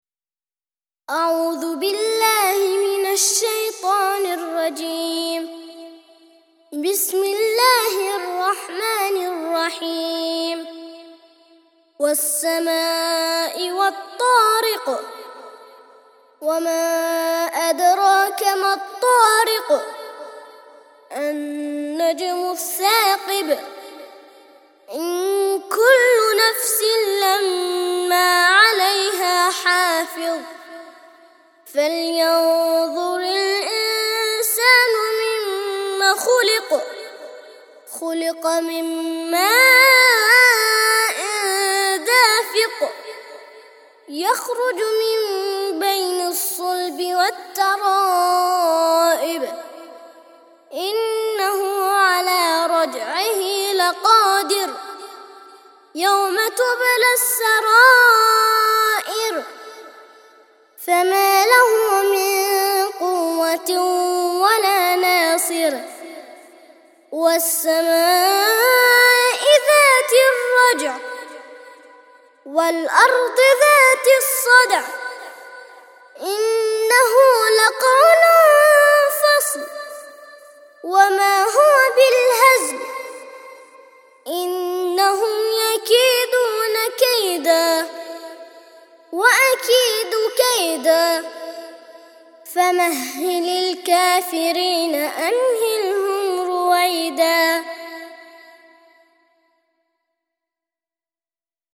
86- سورة الطارق - ترتيل سورة الطارق للأطفال لحفظ الملف في مجلد خاص اضغط بالزر الأيمن هنا ثم اختر (حفظ الهدف باسم - Save Target As) واختر المكان المناسب